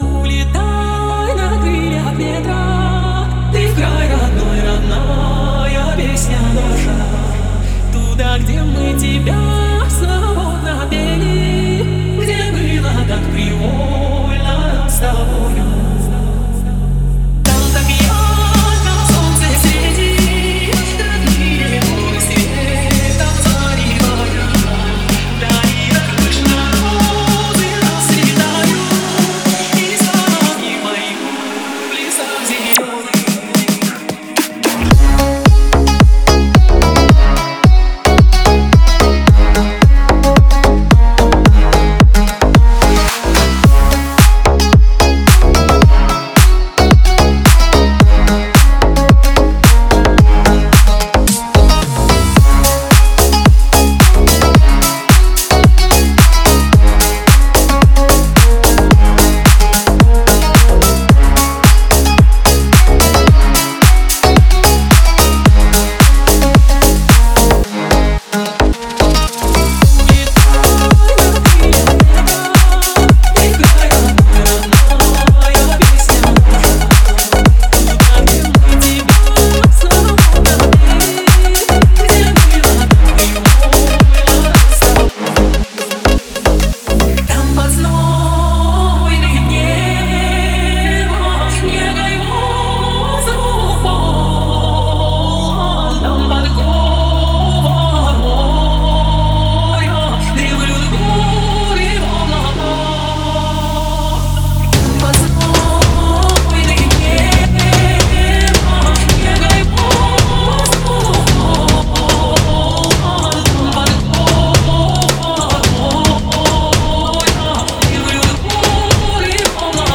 Клубная музыка